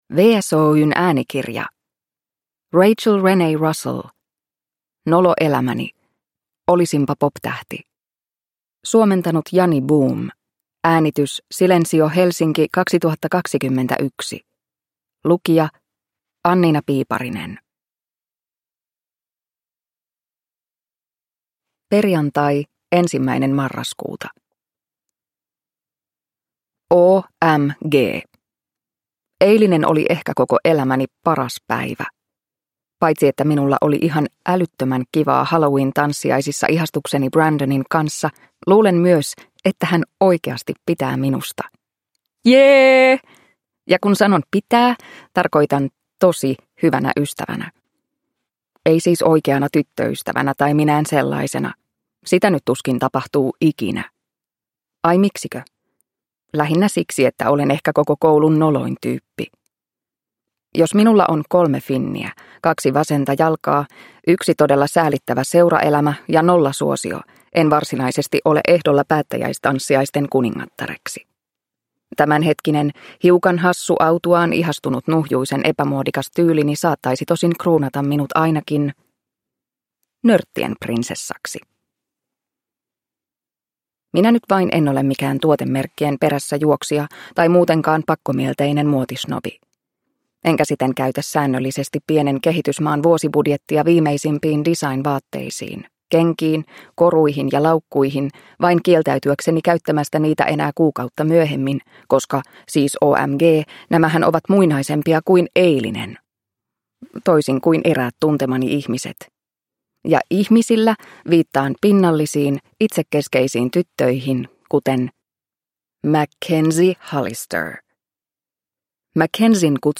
Nolo elämäni: Olisinpa poptähti – Ljudbok – Laddas ner